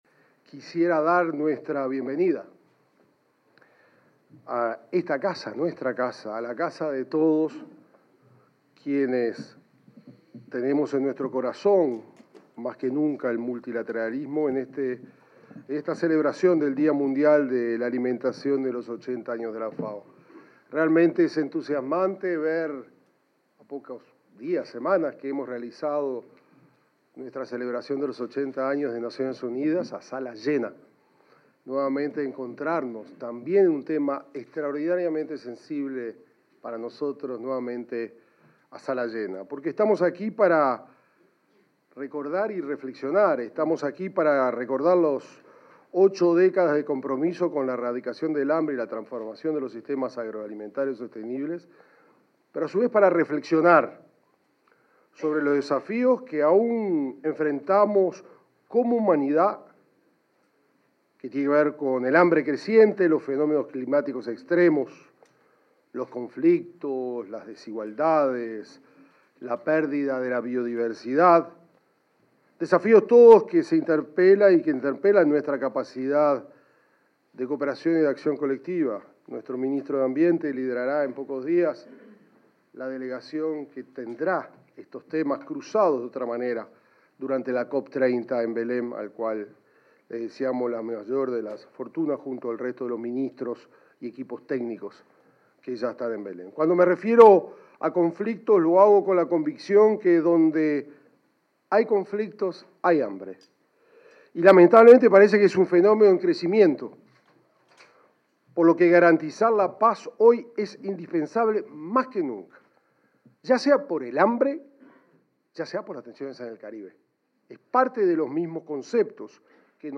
Palabras del canciller Mario Lubetkin y el subsecretario de Ganadería, Matías Carámbula
Palabras del canciller Mario Lubetkin y el subsecretario de Ganadería, Matías Carámbula 14/11/2025 Compartir Facebook X Copiar enlace WhatsApp LinkedIn Durante la conmemoración de los 80 años de la Organización de las Naciones Unidas para la Alimentación y la Agricultura (FAO), se expresaron el ministro de Relaciones Exteriores, Mario Lubetkin, y el subsecretario de Ganadería, Matías Carámbula.